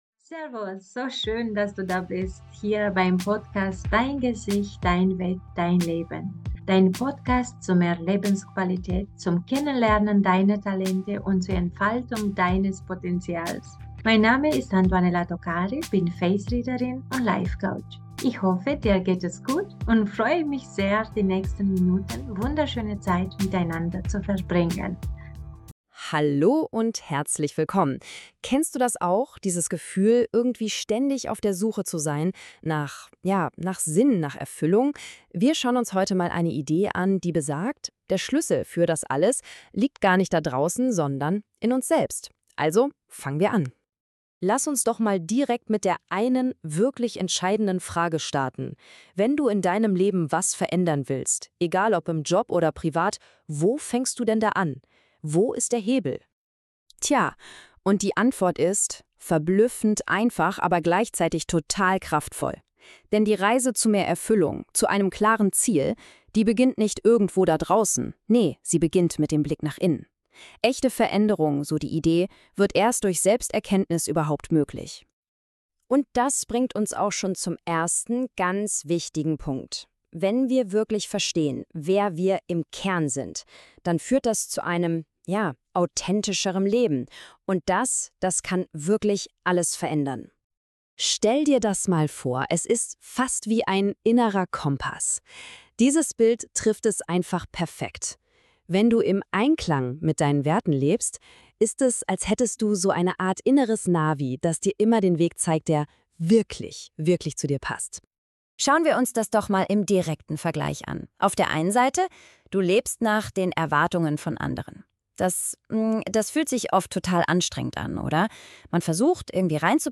Hinweis: Dieses Interview wurde mit Unterstützung von KI